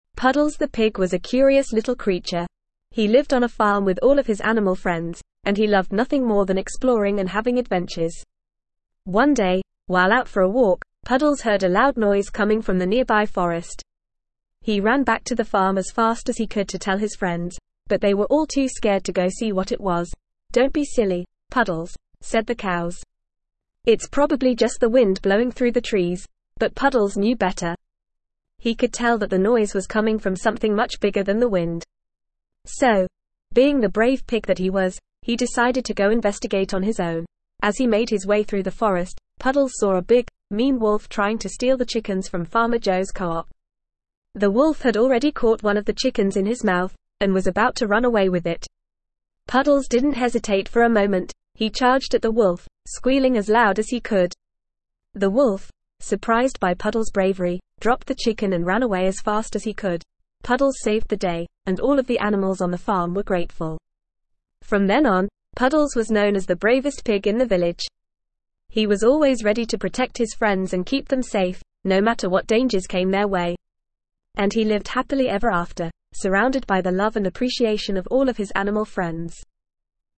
Normal
ESL-Short-Stories-for-Kids-NORMAL-reading-Puddles-the-Brave-Pig.mp3